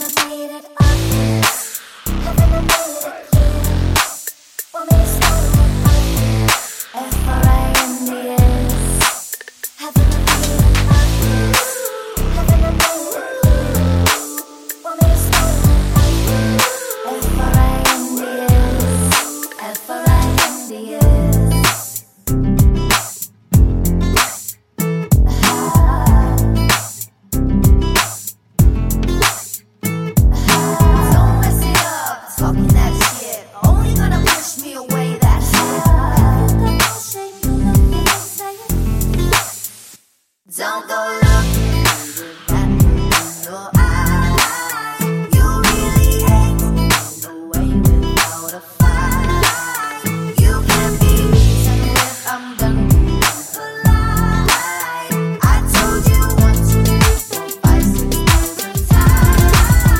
With Clean Backing Vocals Pop (2010s) 3:25 Buy £1.50